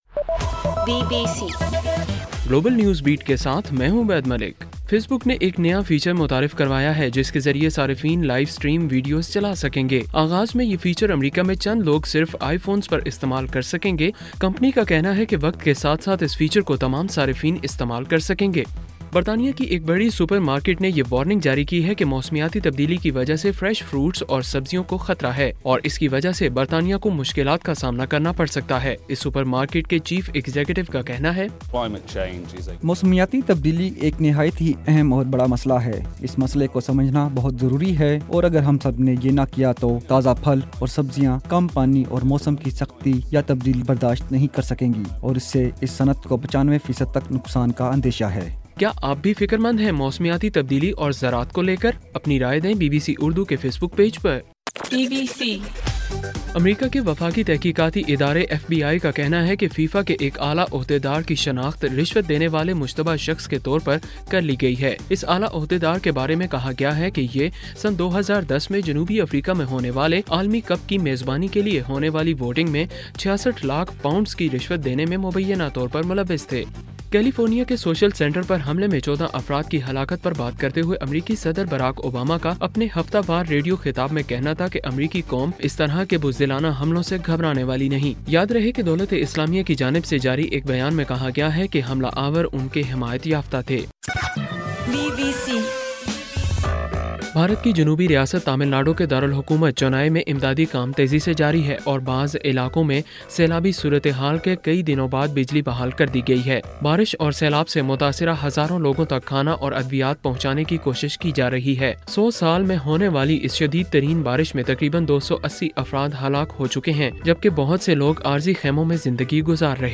دسمبر 5: رات 9 بجے کا گلوبل نیوز بیٹ بُلیٹن